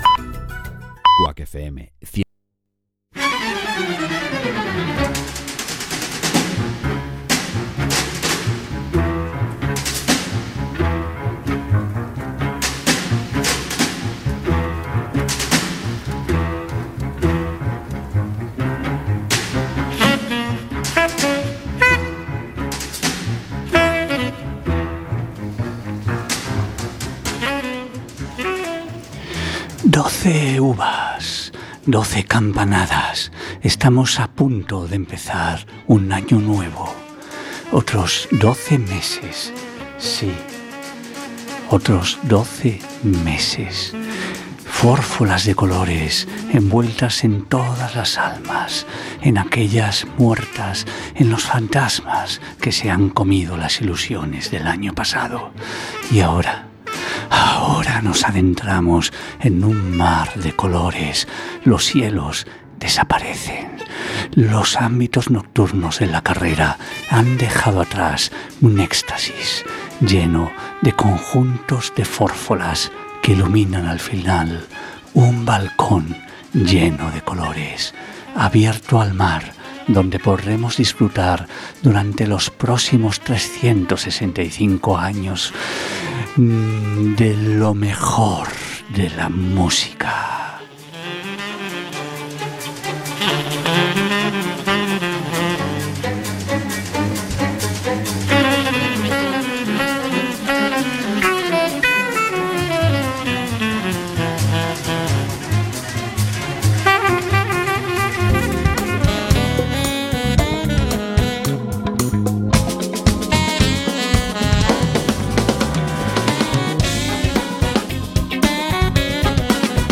En la noche los taxistas y el jazz son los verdaderos protagonistas. Desde el inicio recorreremos en taxi un viaje ficticio hacia El Balcón donde entraremos al bar de Jack, que pondrá los mejores temas de jazz de todos los tiempos hasta hoy. Nos encontraremos con personas importantes y no tan importantes en el camino a las que entrevistaremos e invitaremos a venir con nosotros.